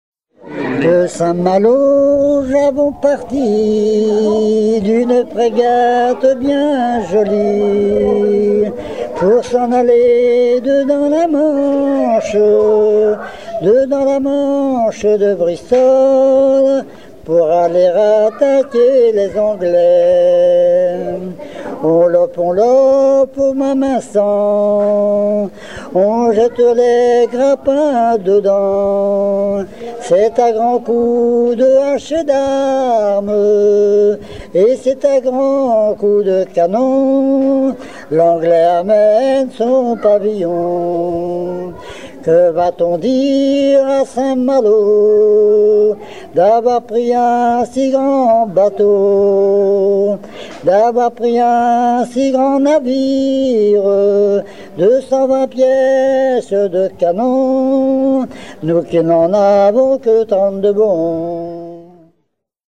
collecté lors d'une viellée-cabaret
Genre strophique
Pièce musicale éditée